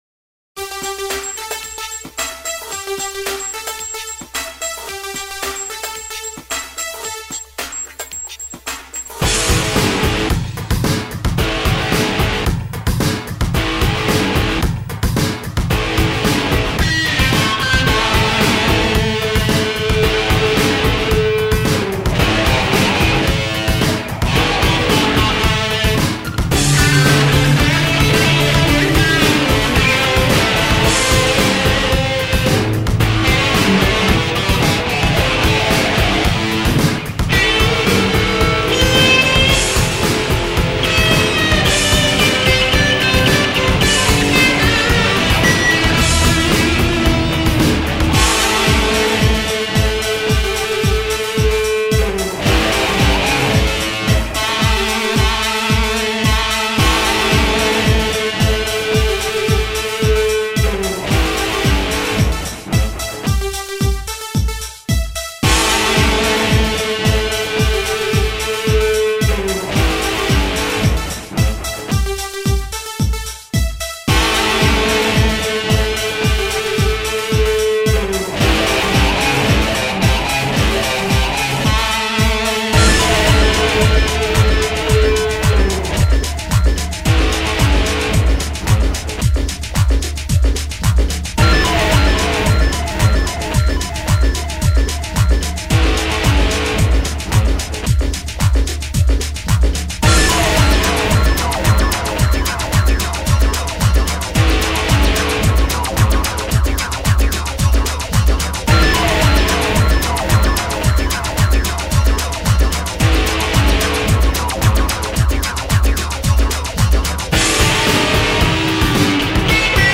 それくらい存在感あるギターですね！